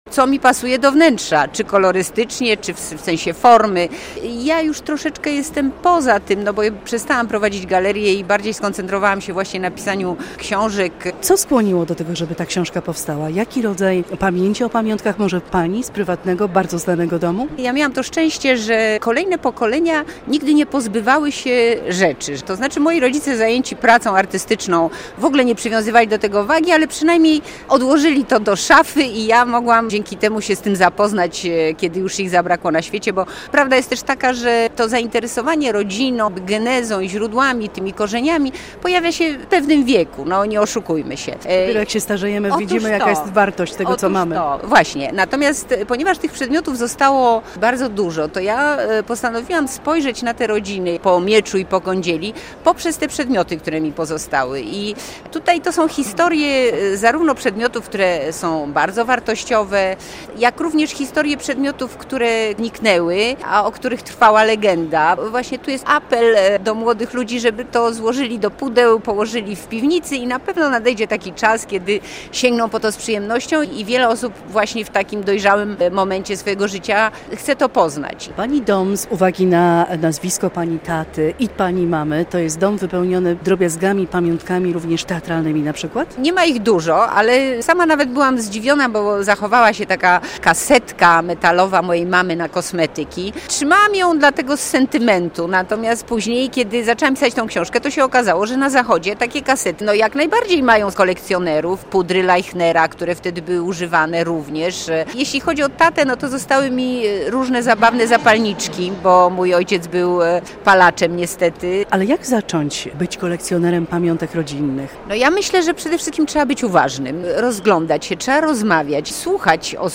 Studio Radia Bialystok